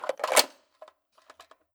Index of /server/sound/weapons/cw_l96